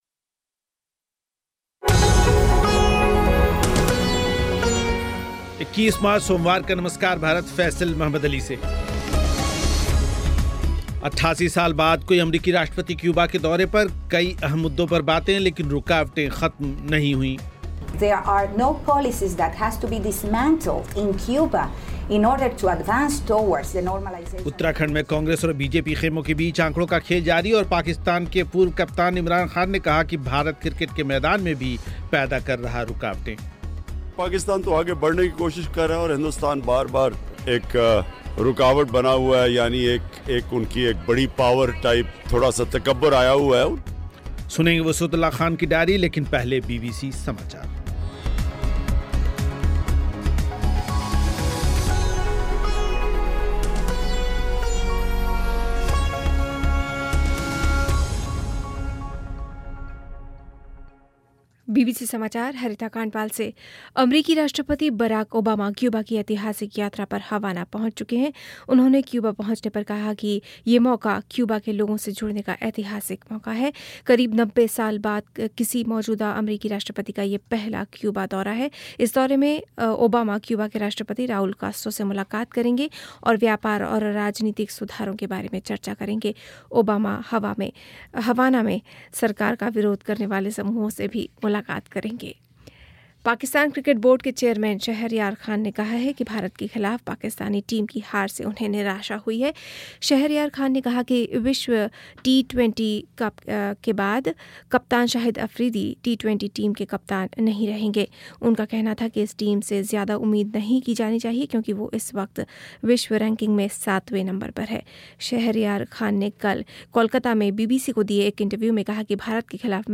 88 साल बाद कोई अमरीकी राष्ट्रपति क्यूबा के दौरे पर, कई अहम मुद्दों पर बातें, लेकिन रूकावटें ख़त्म नहीं हुईं हैं, सुनें एक रिपोर्ट
एक संक्षिप्त इंटरव्यू